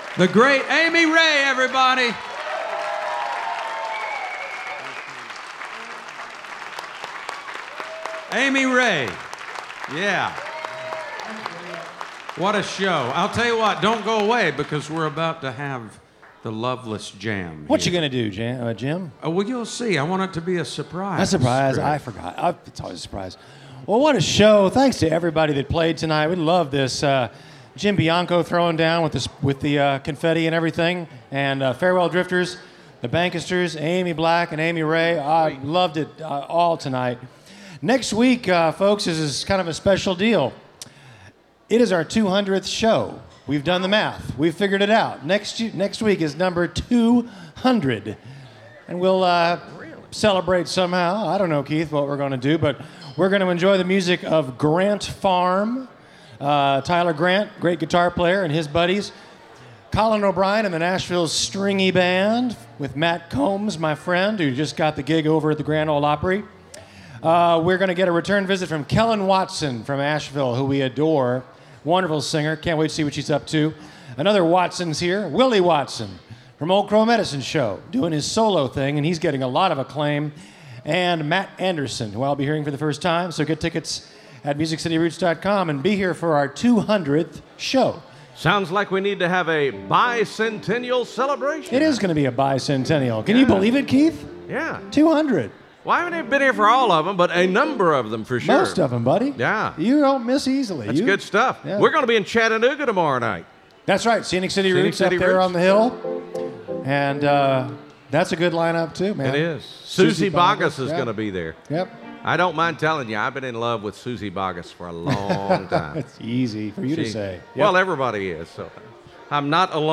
(recorded from webcast)
08. announcer (4:28)